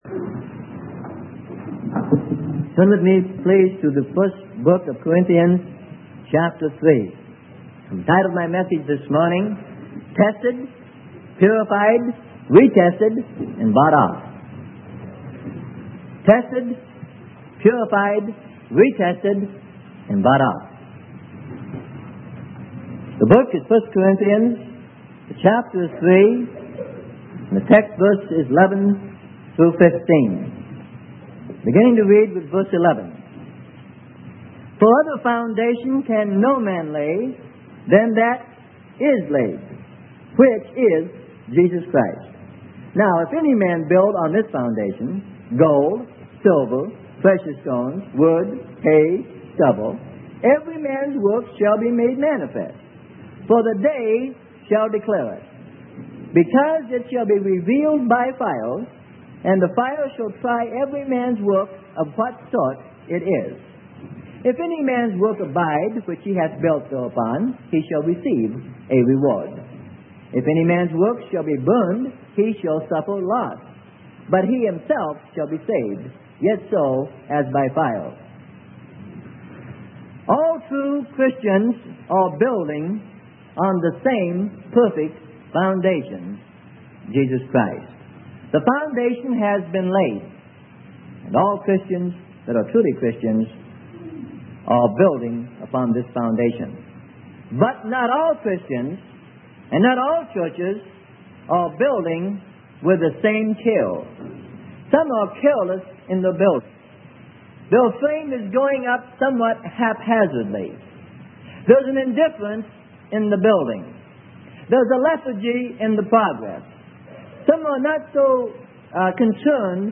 Sermon: Tested Purified Re-tested - Freely Given Online Library